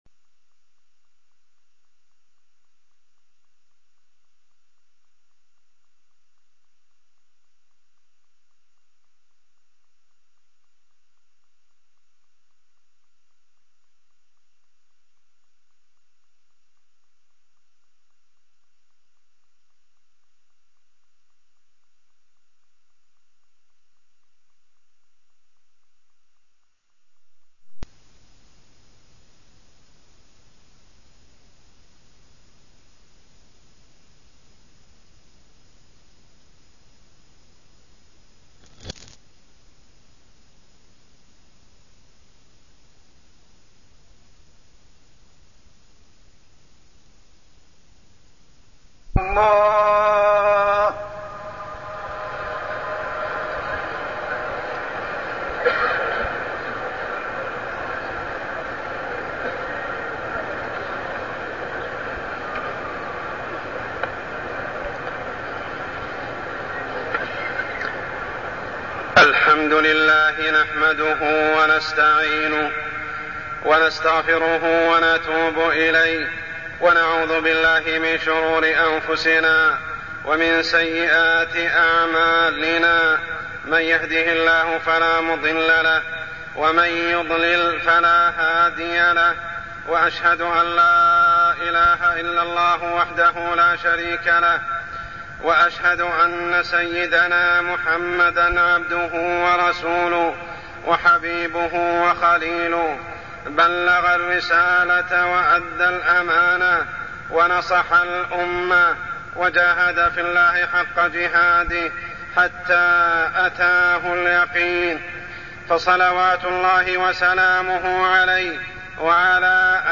تاريخ النشر ٩ ذو الحجة ١٤١٩ هـ المكان: المسجد الحرام الشيخ: عمر السبيل عمر السبيل الجمع بين يوم عرفة و يوم الجمعة